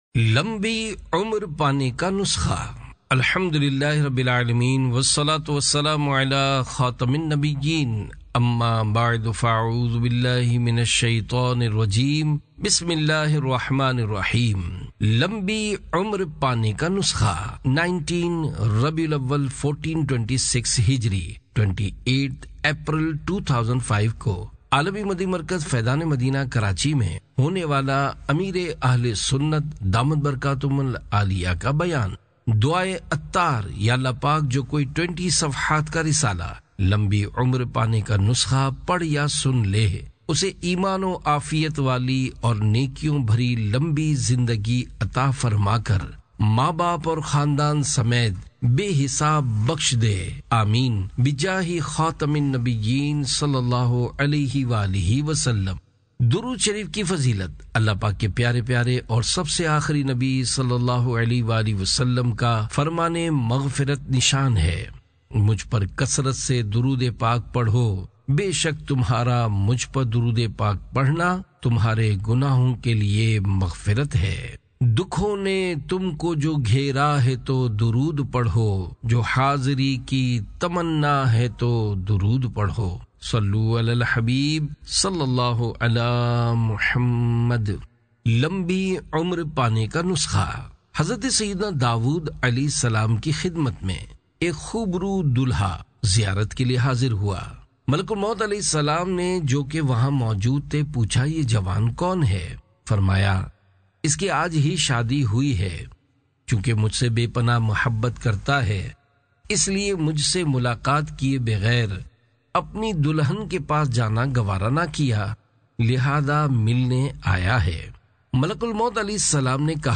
Audiobook - Lambi Umer Pane Ka Nuskha (Urdu)